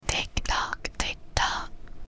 (Girl Whispering) - Tick Tock | TLIU Studios
Category: ASMR Mood: Relax Editor's Choice